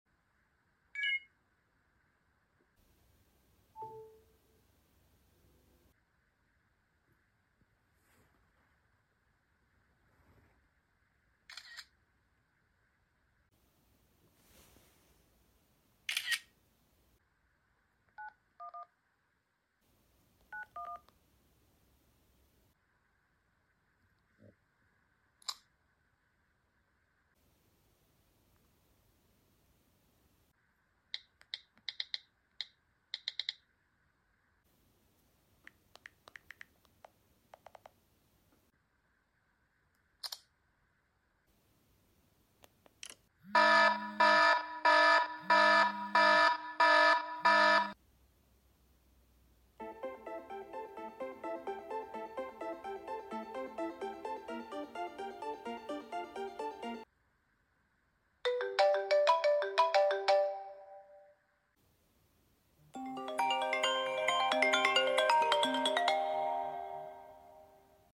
iOS 1 vs. iOS 17 sound effects free download
iOS 1 vs. iOS 17 Sounds